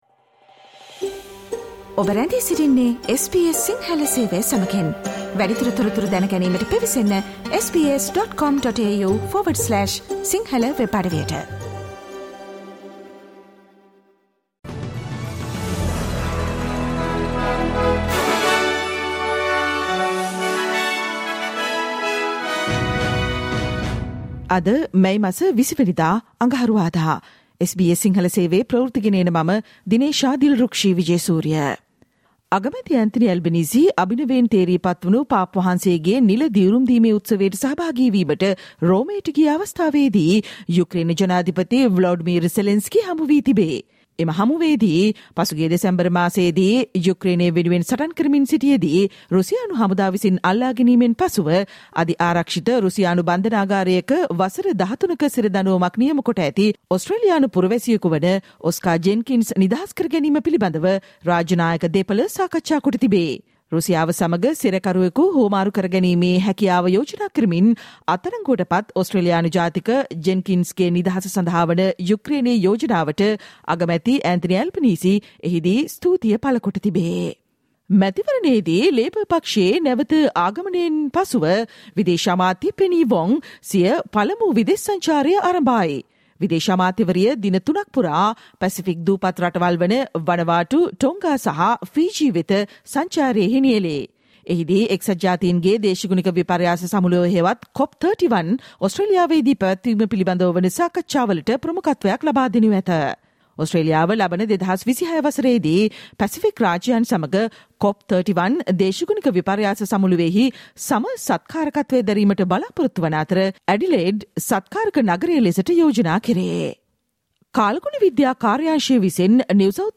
SBS සිංහල Newsflash මැයි 20: නිව් සවුත්වේල්ස් ප්‍රාන්තයට අයහපත් කාලගුණය සහ ගංවතුර ගැන අනතුරු ඇඟවීම්